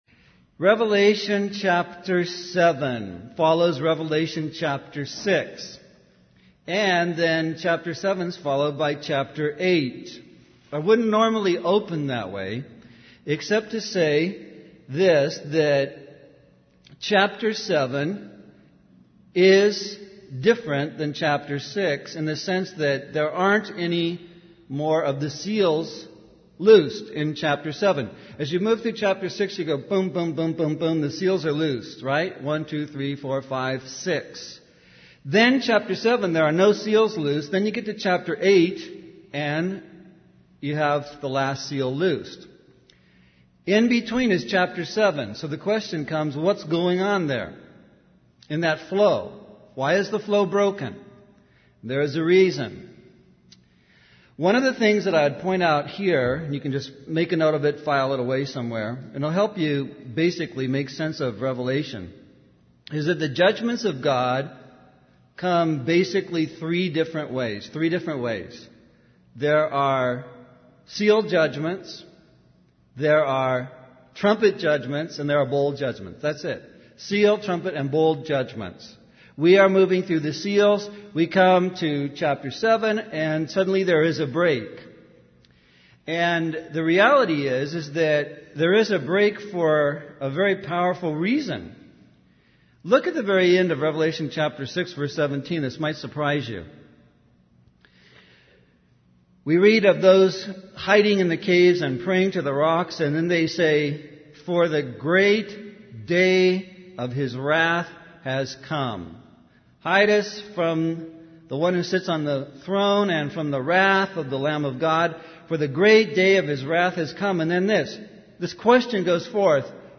In this sermon, the preacher focuses on the judgment restrained as described in Revelation 7:1. He highlights the presence of four powerful angels standing at the four corners of the earth, holding back the winds of the earth.